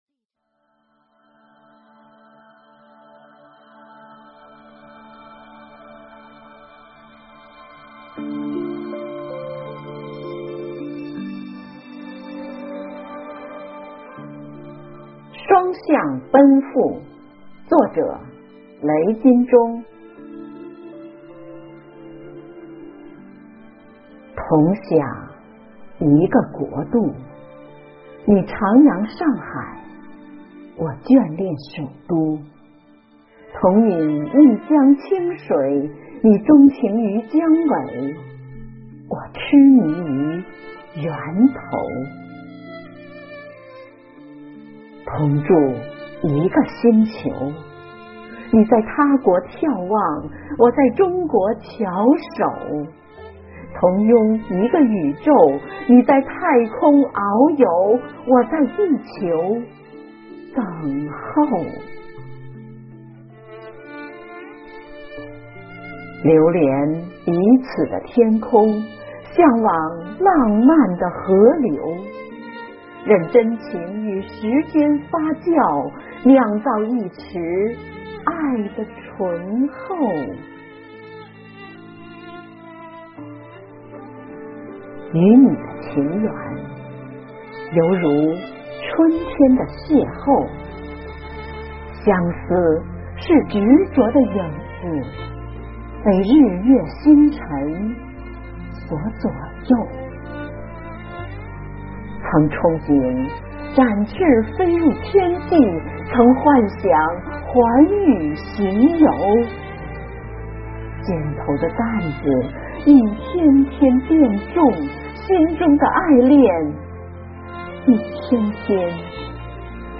诵读：